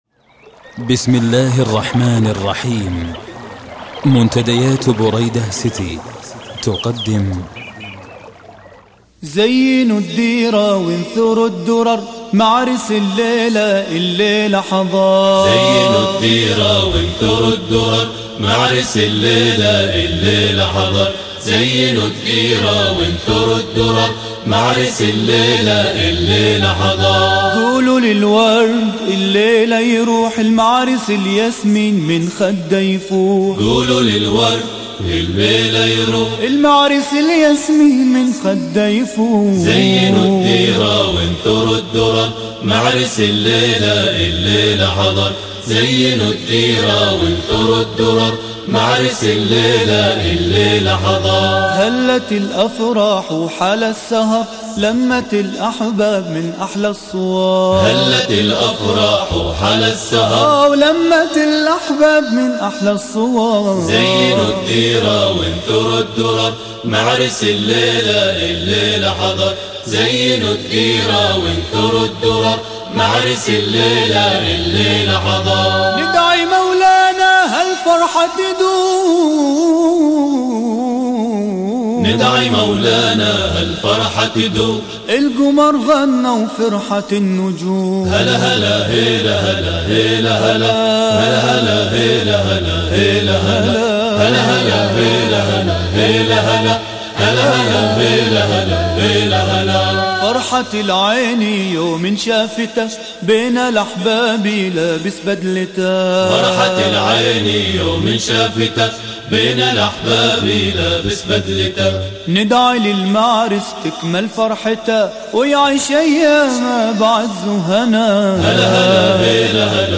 من أروع أناشيد الأفراح الشامية